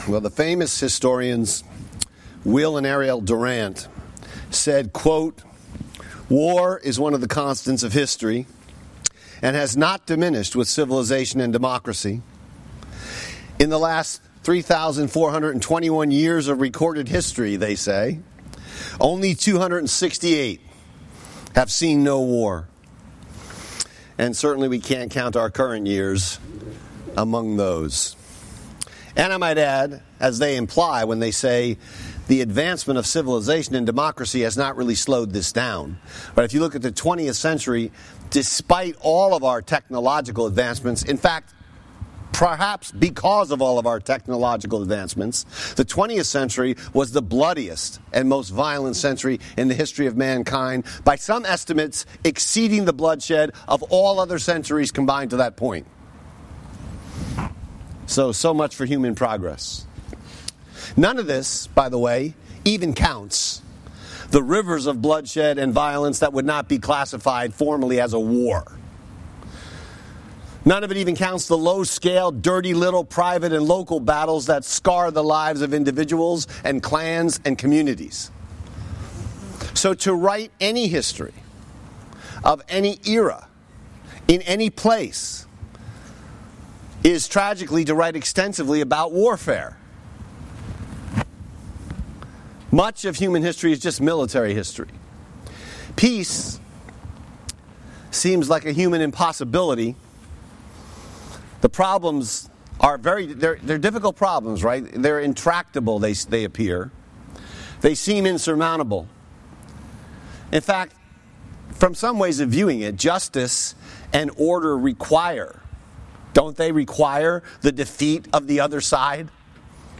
Sermon Text: Matthew 5:1-12